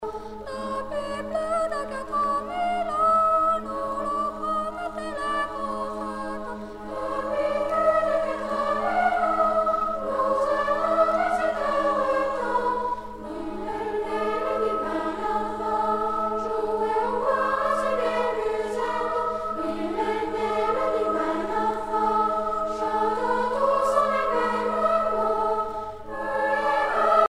Noël
Collège de Montreux (Petit choeur)
Pièce musicale éditée